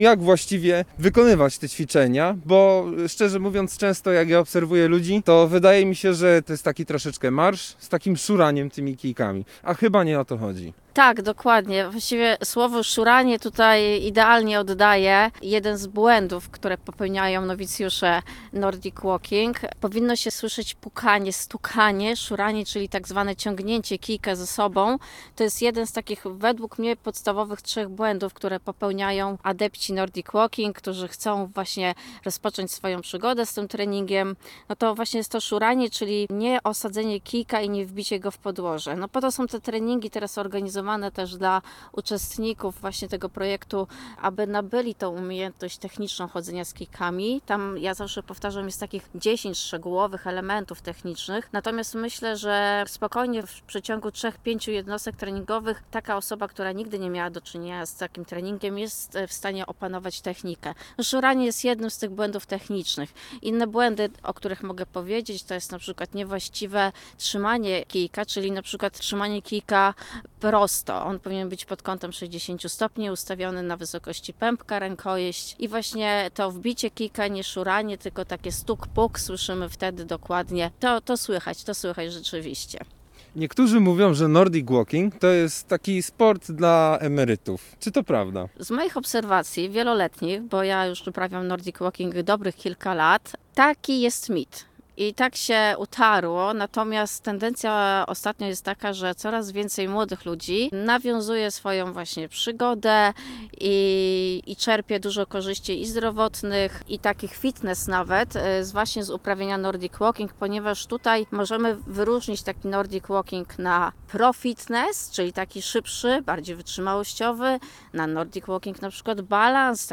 Posłuchaj: Nazwa Plik Autor Jak przygotować się do treningu nordic walking? Rozmowa